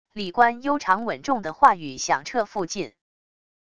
礼官悠长稳重的话语响彻附近wav音频